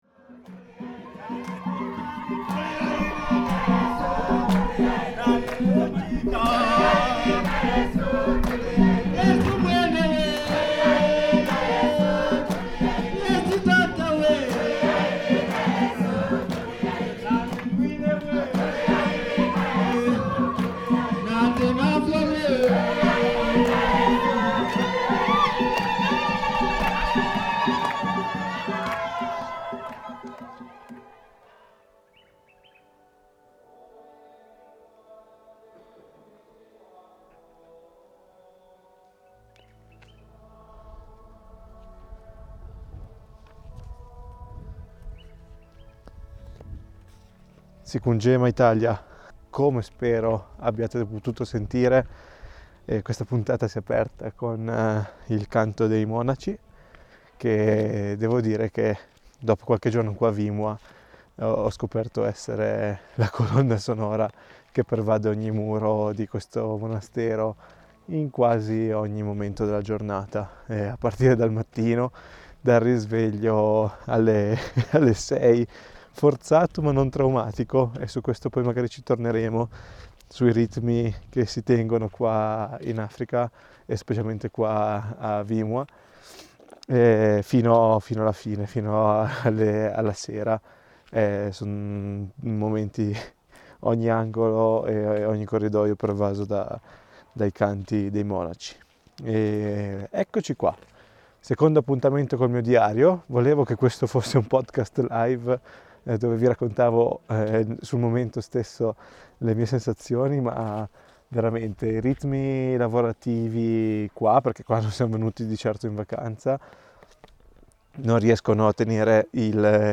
Lottando contro una voce che non vuole collaborare, in questa puntata andiamo all'esplorazione di Mvimwa